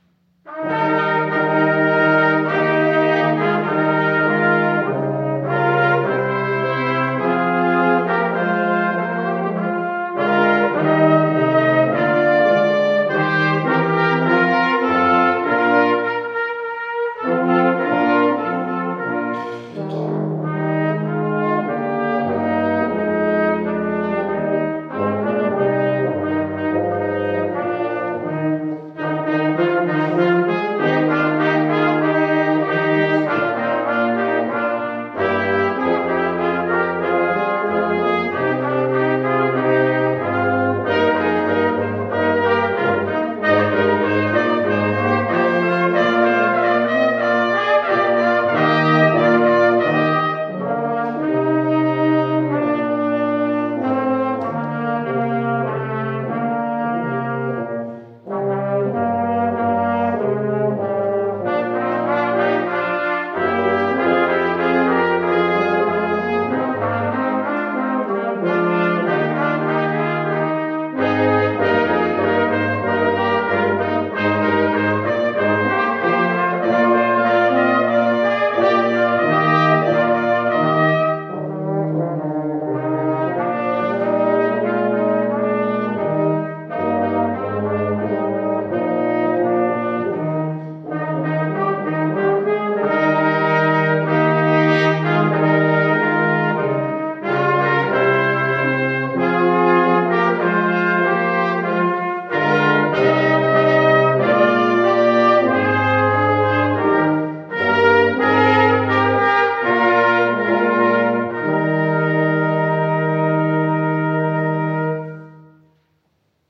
Posaunenchor
von CVJM-Brass
Wie-soll-ich-dich-empfangen-Vorspiel.wav